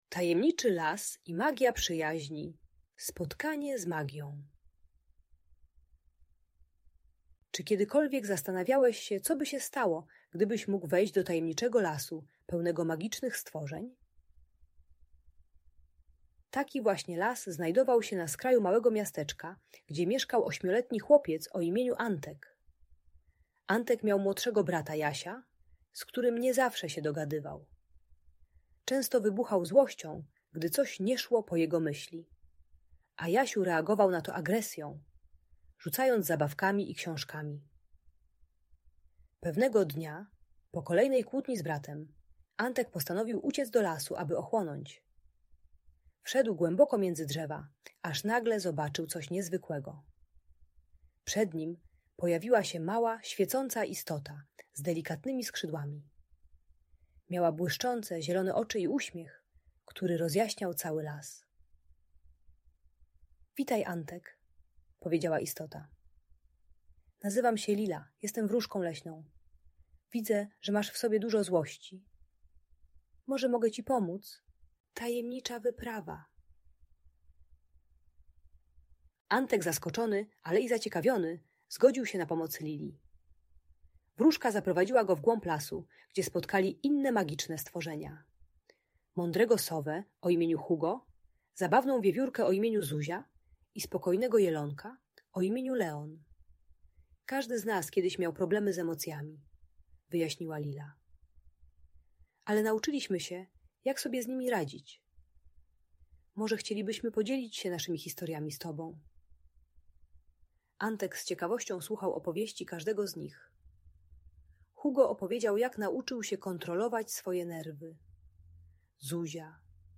Tajemniczy Las i Magia Przyjaźni - Audiobajka dla dzieci